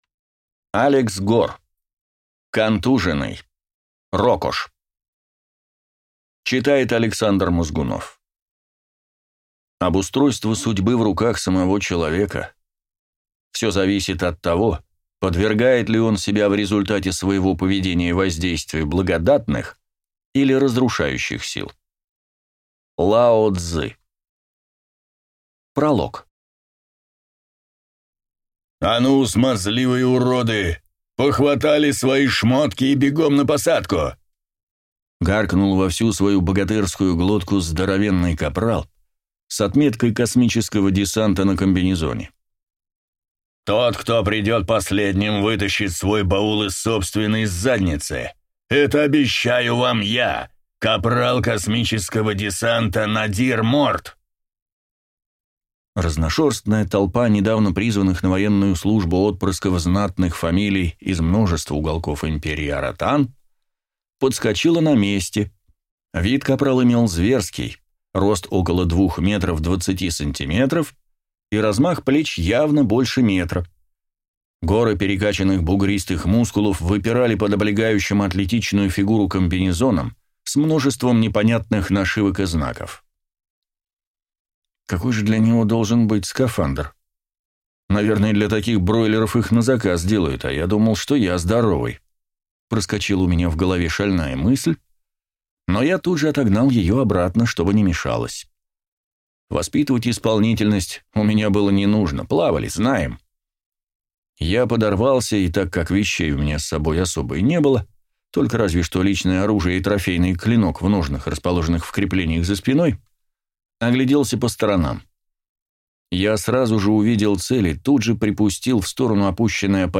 Аудиокнига Контуженный: РОКОШ | Библиотека аудиокниг